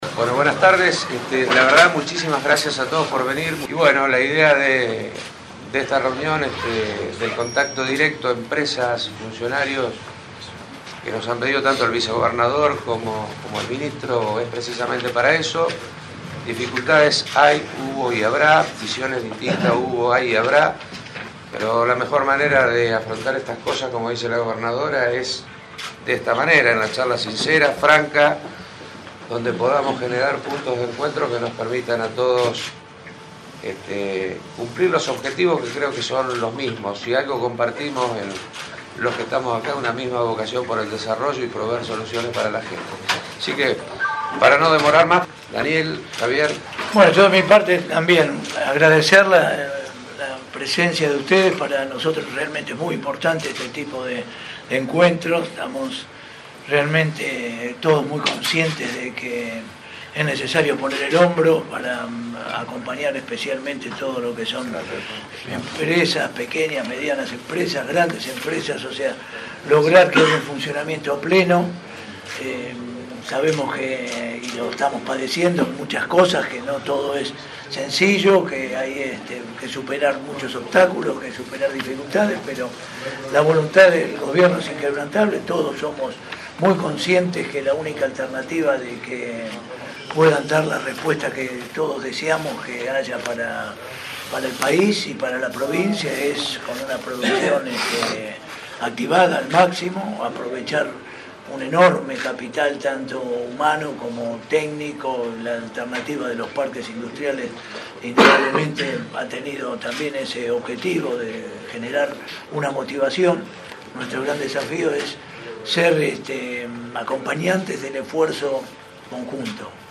Esta visita provocó muchísimo interés en los empresarios locales quienes acudieron a la cita con muchas expectativas y en un muy importante número de asistentes para conocer el mensaje de los principales actores de la provincia de Bs. As.
charla-con-empresarios-CORTADA.mp3